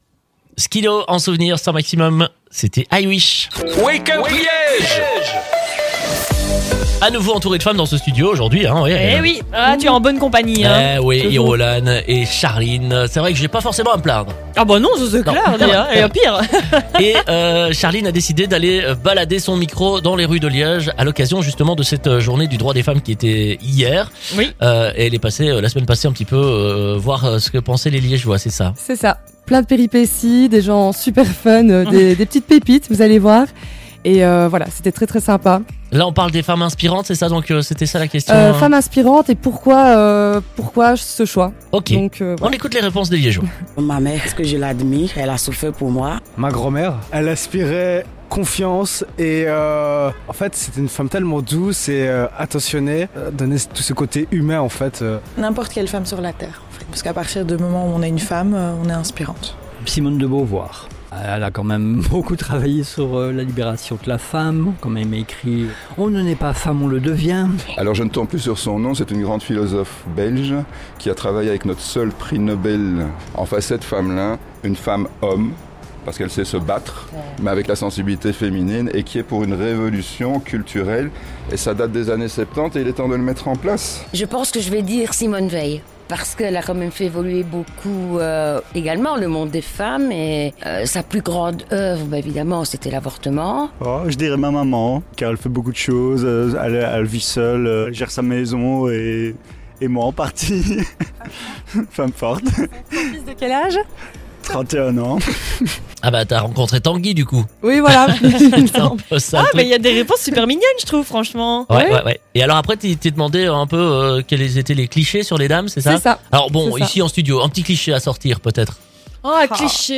MICRO-TROTTOIR À LIÈGE POUR LA JOURNÉE INTERNATIONALE DES DROITS DES FEMMES